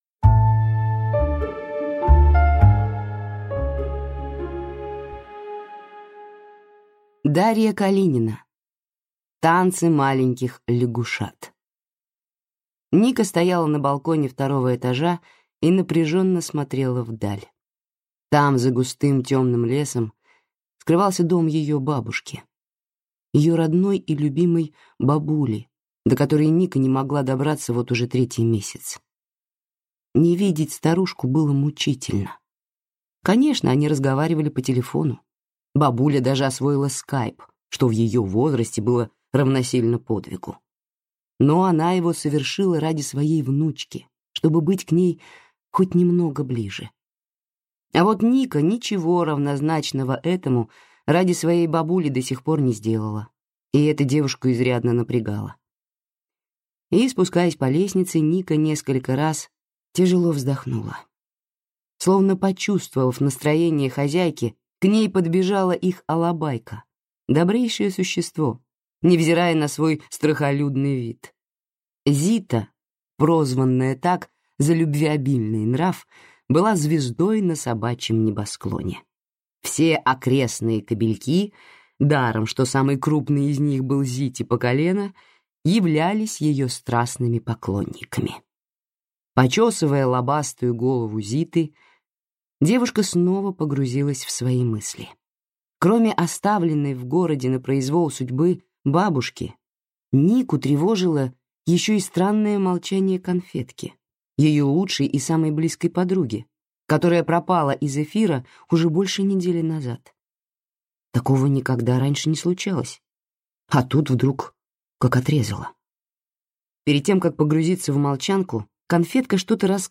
Аудиокнига Танцы маленьких лягушат | Библиотека аудиокниг